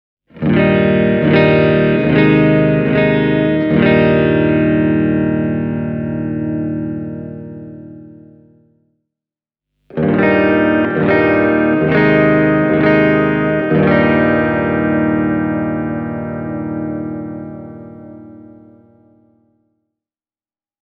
Säälimättömästä lähtötehostaan huolimatta molemmista humbuckereista löytyy yllättävän musikaalinen ja täyteläinen diskantti.
Tässä on kaksi perussoundit esittelevää klippiä, jotka äänitin Blackstar HT-1R -putkikomboa käyttäen: